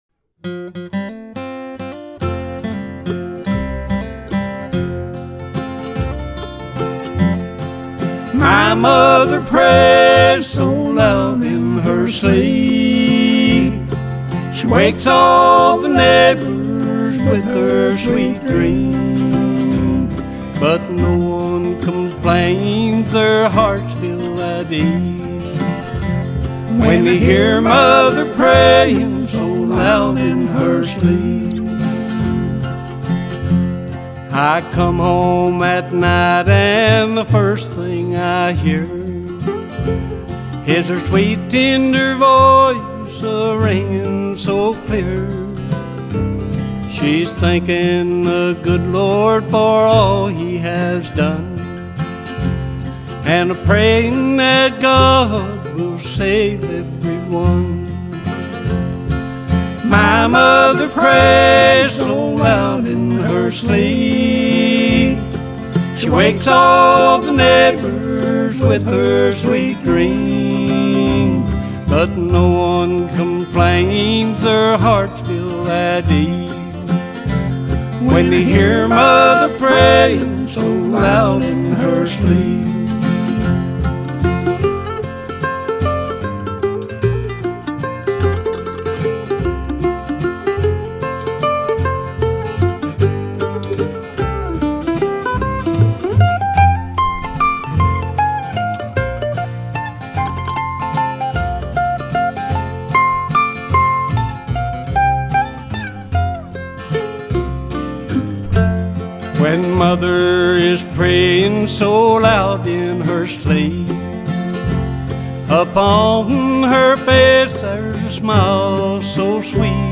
Banjo & Vocal
Mandolin & Vocal
Bass & Vocal
Guitar & Vocal